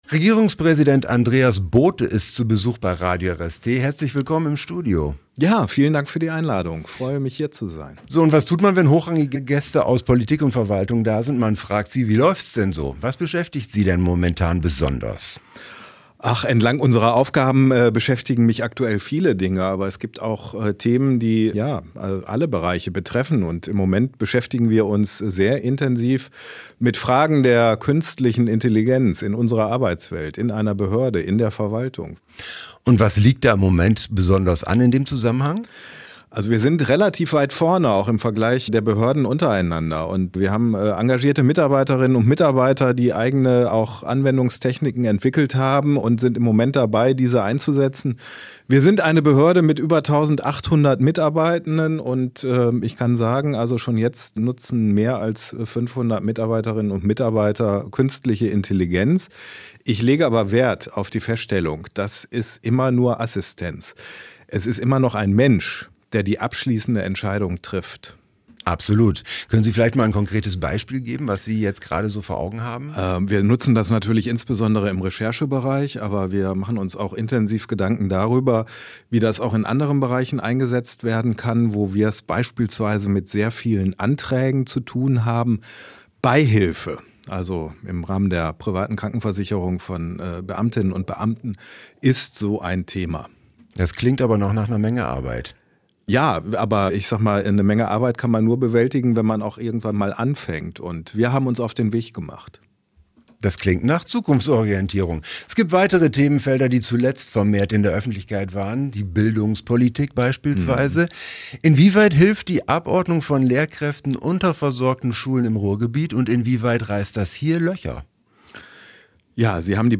Veröffentlicht: Freitag, 12.09.2025 11:56 Anzeige play_circle play_circle Interview mit Regierungspräsident Andreas Bothe download play_circle Abspielen download Anzeige